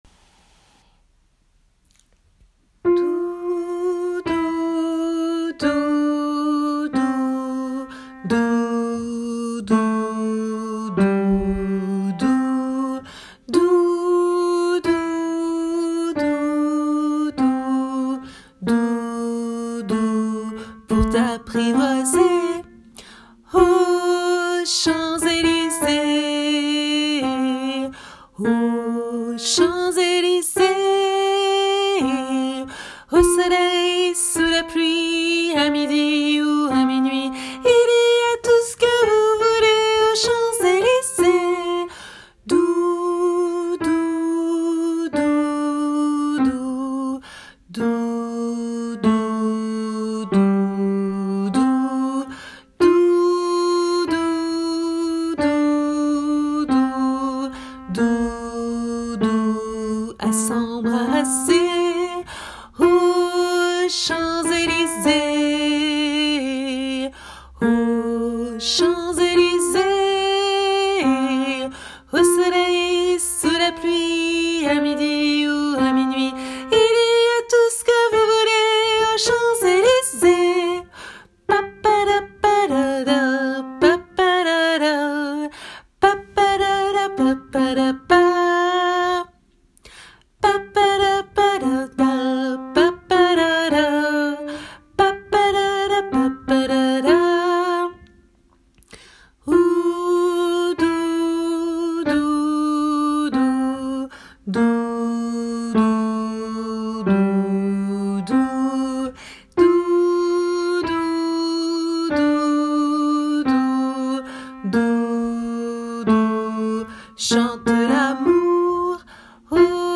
MP3 versions chantées (les audios sont téléchargeables)
Basse